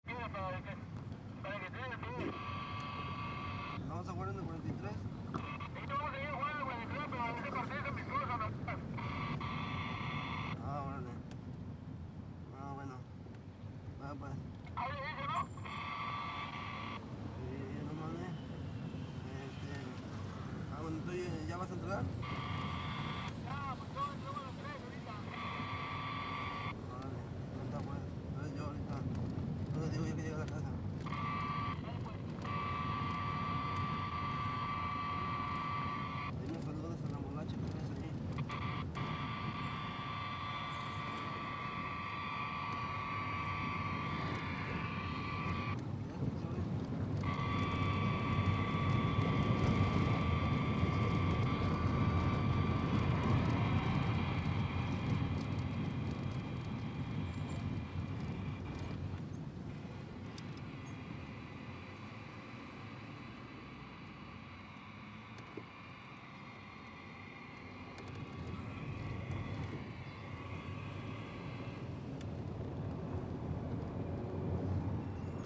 Звук радио переговоры голоса в эфире